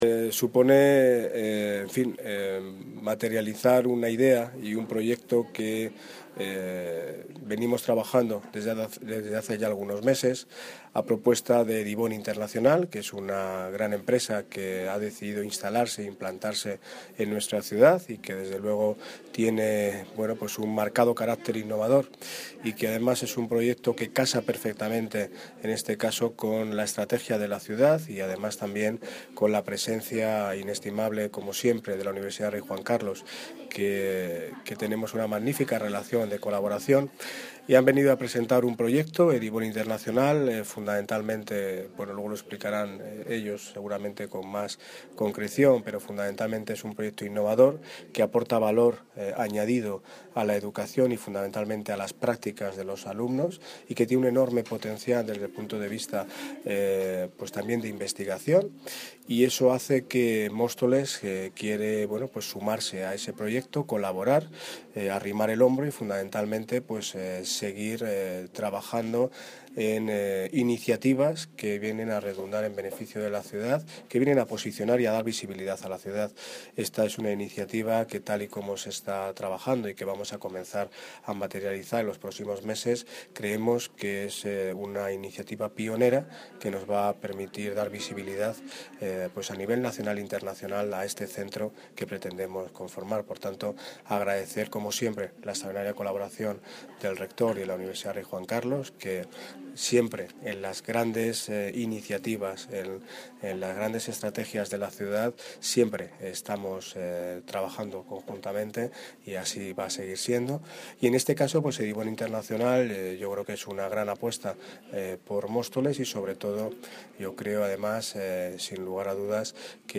Audio - Daniel Ortiz (Alcalde de Móstoles) Sobre Primer Cantro de Educación Remota